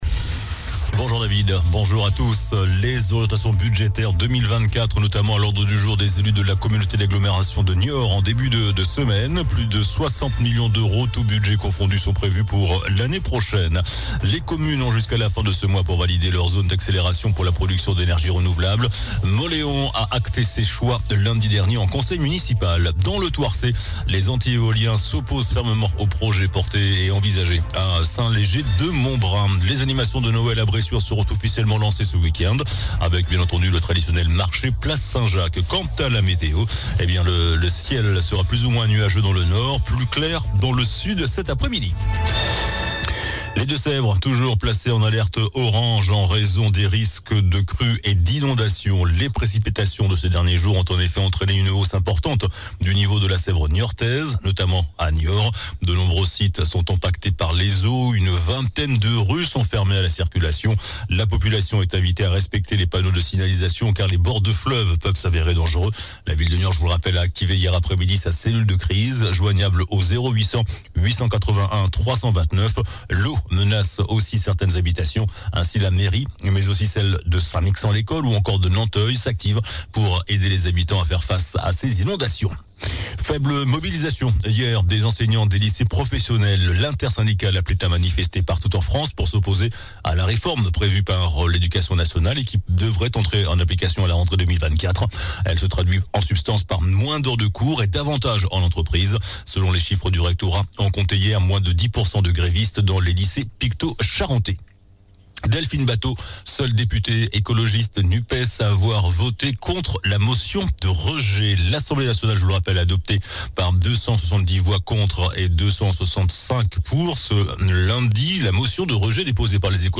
JOURNAL DU MERCREDI 13 DECEMBRE ( MIDI )